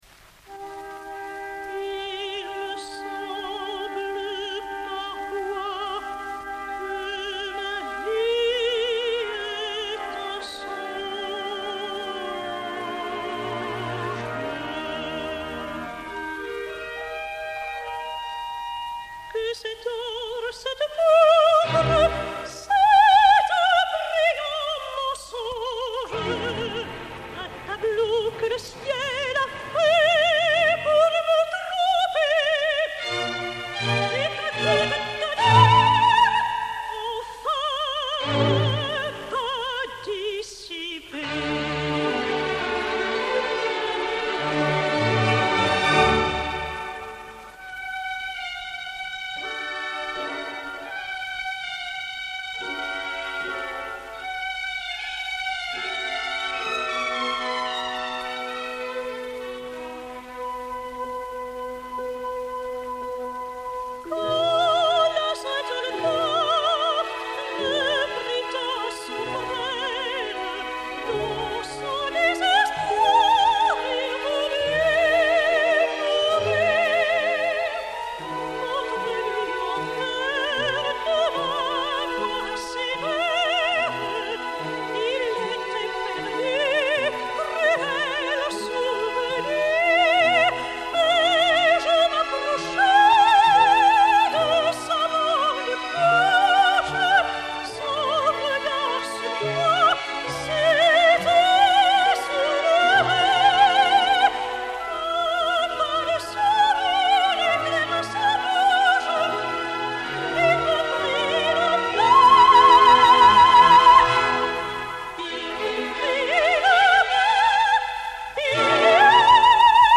Extraits enregistrés en 1958 (révision musicale d'Henri Büsser) :
Chœurs et Orchestre National de la Radiodiffusion Française dir. Georges Tzipine (chef des chœurs René Alix)
06. Acte III - Air (Marie)